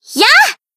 BA_V_Mika_Battle_Shout_1.ogg